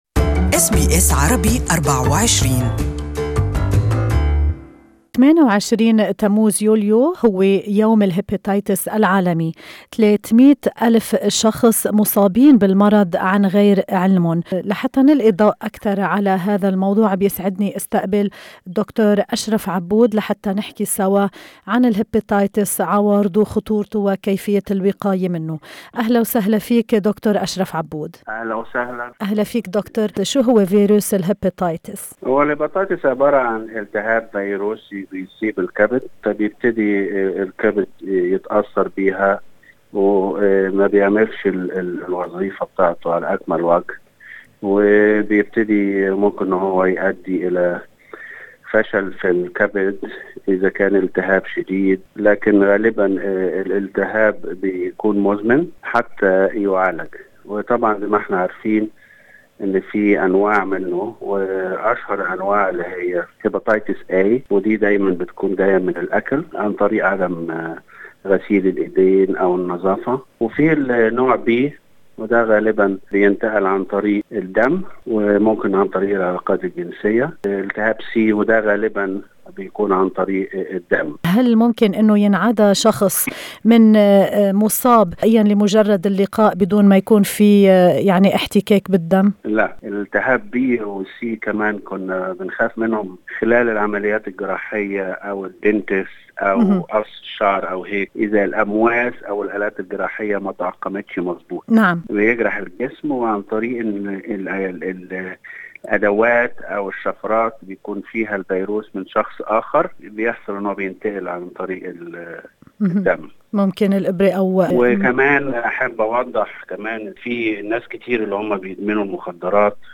طبيب الصحة العامة